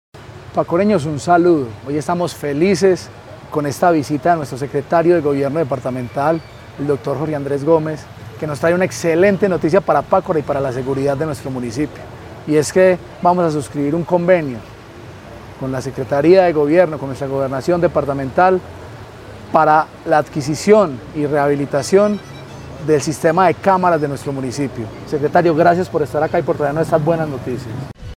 Juan Camilo Isaza, alcalde de Pácora.
Juan-Camilo-Isaza-alcalde-de-Pacora.mp3